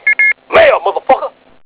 Euro Trip Sound Bites
Sfx: Email alert beeps.
mail.wav